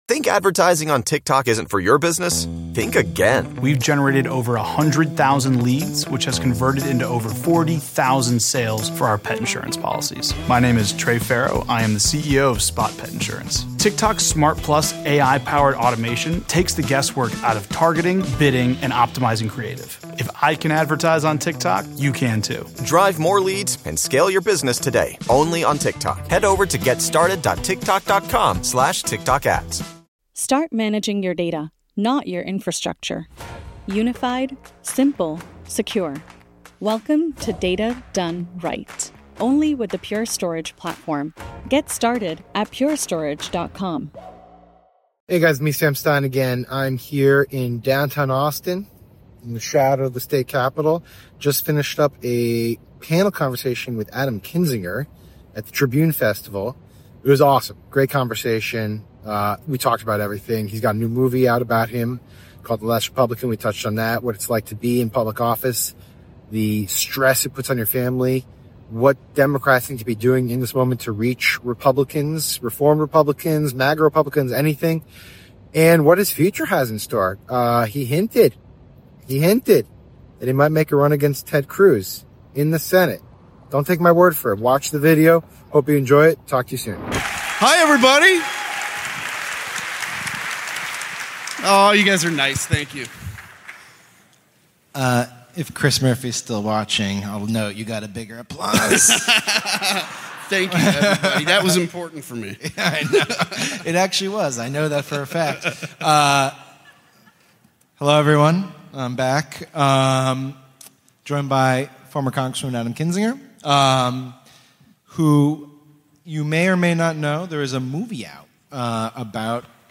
NOTE: There are a few moments when the video cuts out. We apologize for these issues with the recording.
Sam Stein sits down with former Congressman Adam Kinzinger at the Texas Tribune Festival to talk about “The Last Republican,” how the GOP became a personality cult, what January 6 did to his family, McCarthy’s surrender in Mar-a-Lago, the DOJ’s failures, and why winning back ex-MAGA voters means not shaming them.